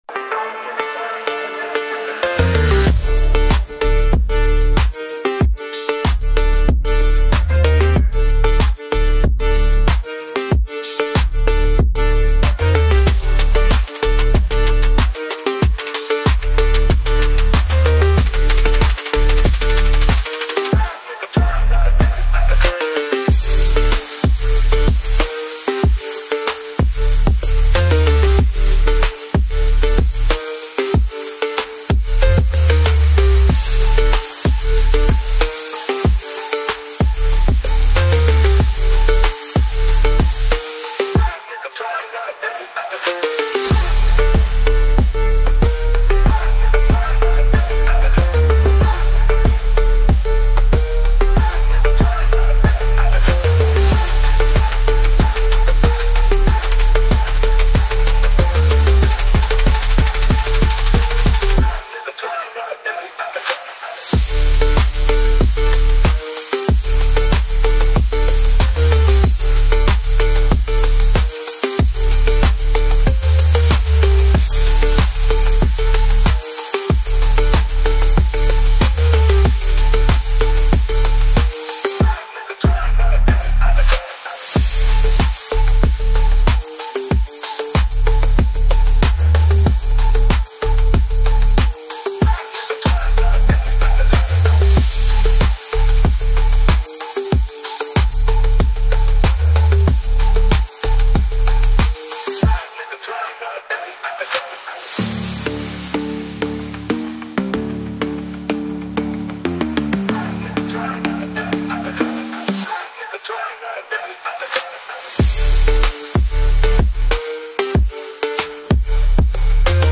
Nhạc Chuông Bùng Nổ Năng Lượng